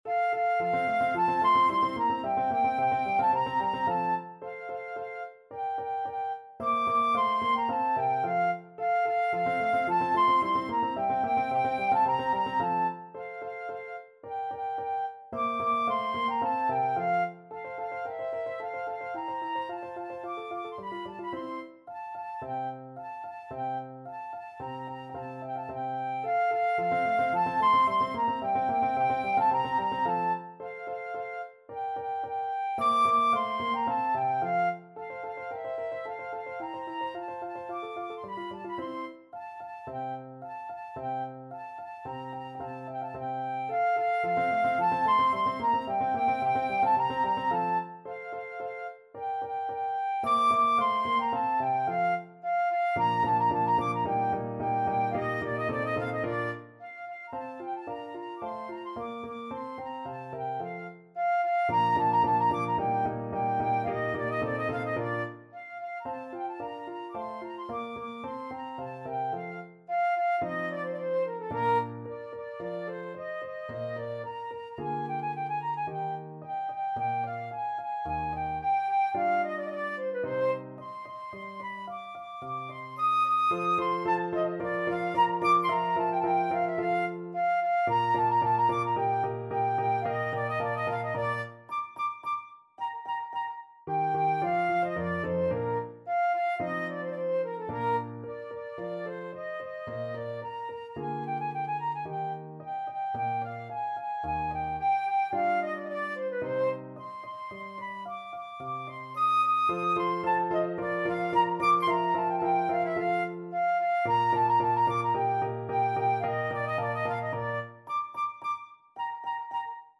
Flute
F major (Sounding Pitch) (View more F major Music for Flute )
Vivace assai =110 (View more music marked Vivace)
A5-Eb7
2/4 (View more 2/4 Music)
Classical (View more Classical Flute Music)